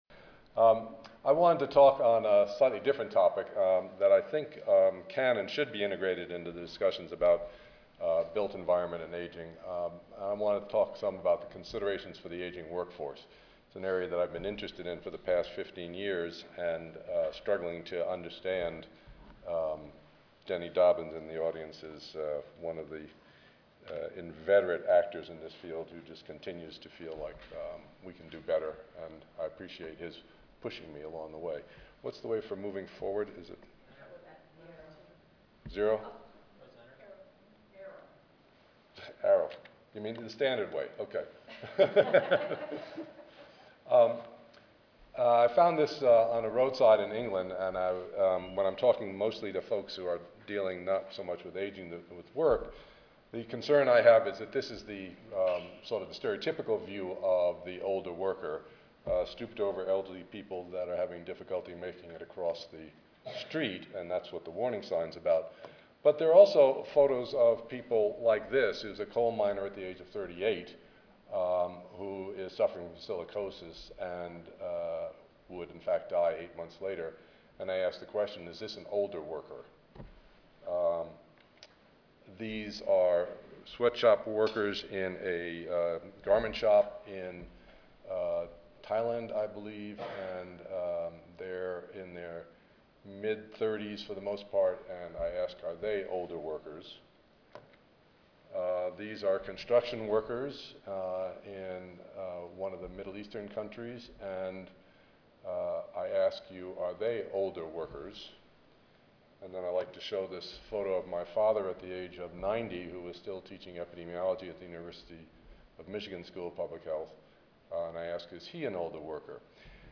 Panel Discussion